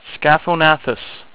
Pronunciation Key
SKA-fo-na-thus